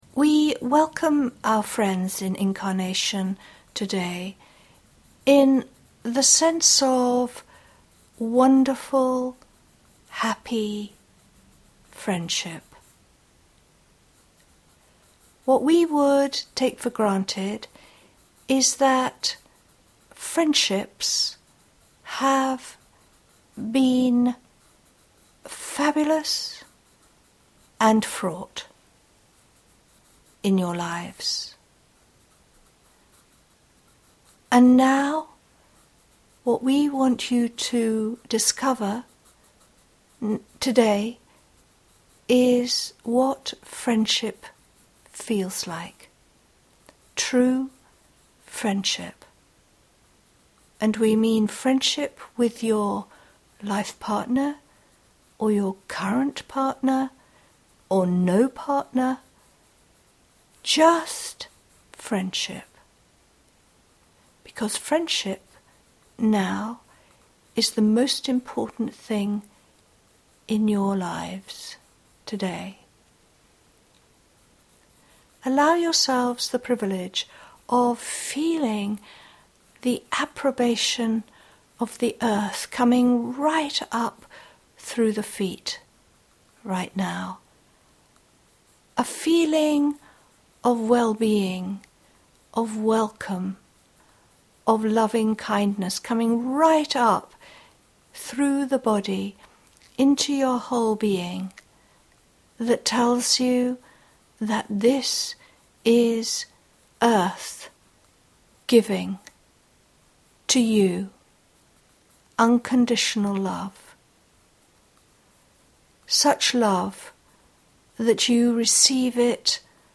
FRIENDSHIP-MEDITATION.mp3